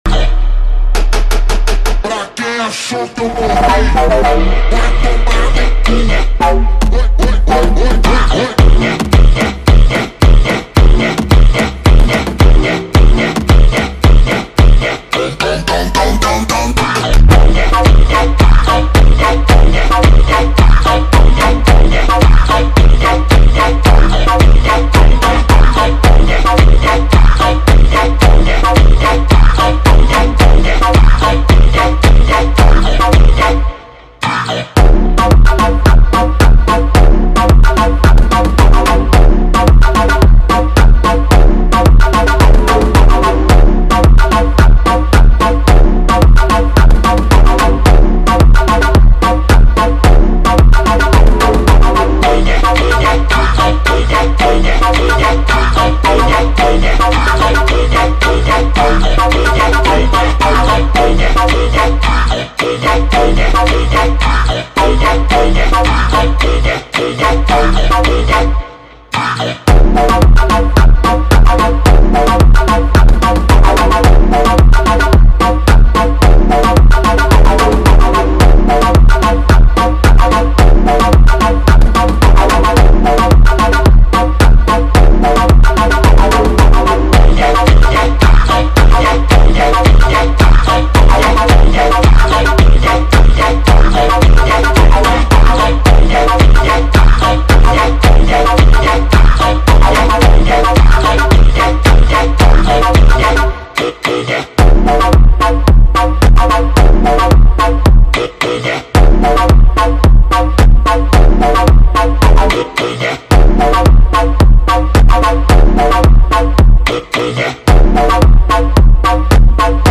• Качество: 320 kbps, Stereo
Slowed Reverb